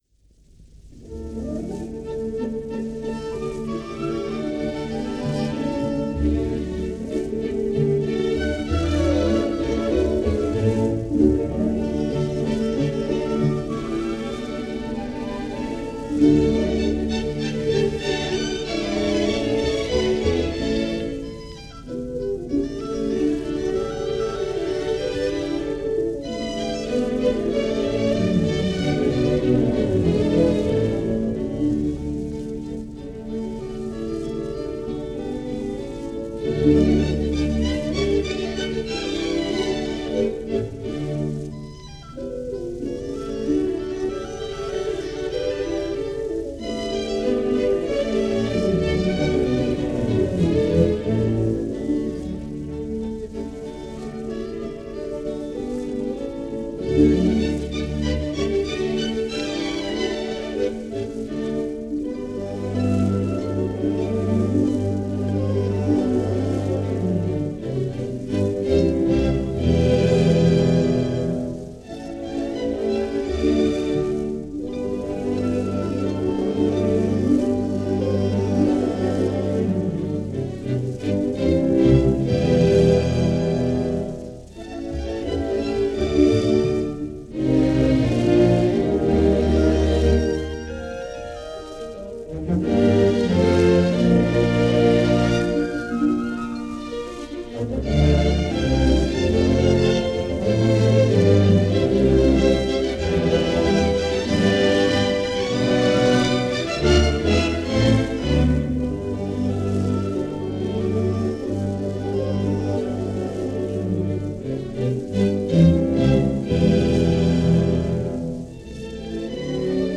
Sten Frykberg With The Swedish Radio Symphony Play music of Wilhelm Peterson-Berger - 1948 - radio broadcast performance from Sveriges Radio.
Wilhelm Peterson-Berger: Soros Flowers (arr. Orch.) – Swedish Radio Symphony – Sten Frykberg, cond.
Over to Sweden this week for some radio performances of less-known works from Sweden with the Swedish Radio Symphony conducted by Sten Frykberg in this 1948 radio broadcast via Sveriges Radio in Stockholm.
Soros Flowers by Wilhelm Peterson-Berger, an orchestration of his popular piano work.